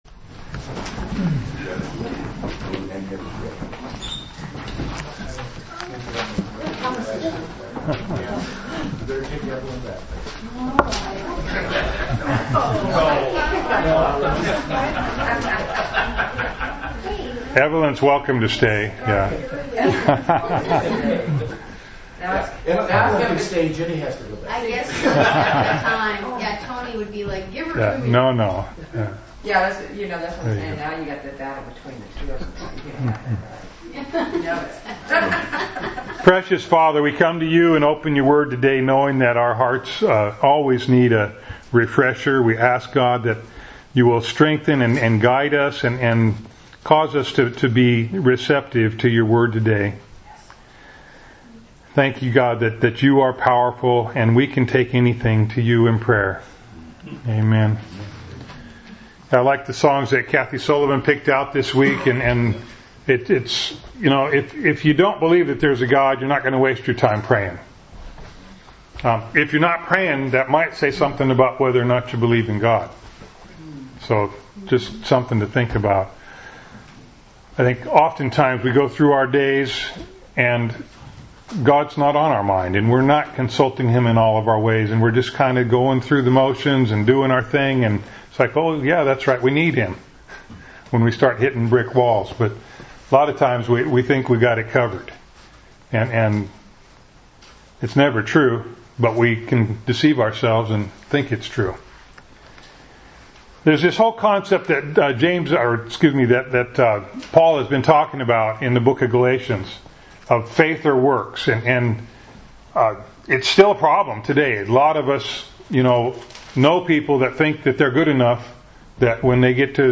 2014 Abraham’s Blessing Preacher